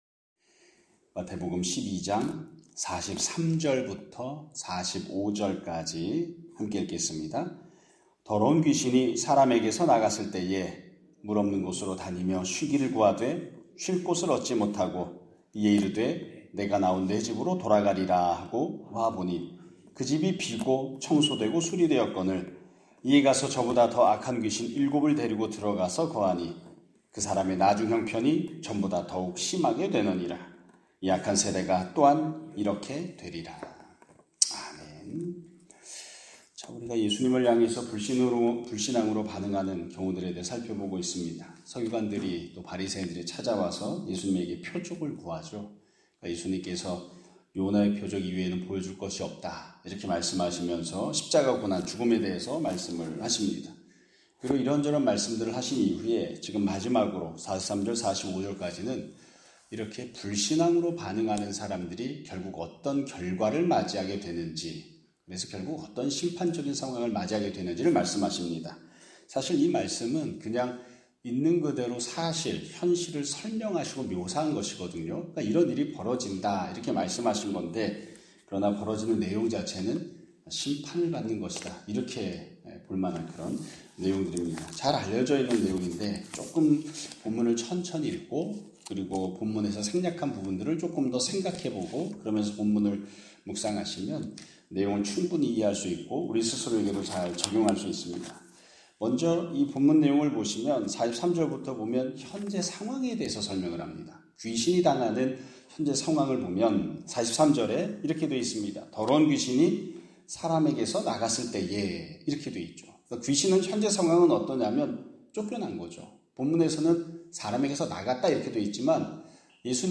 2025년 9월 19일 (금요일) <아침예배> 설교입니다.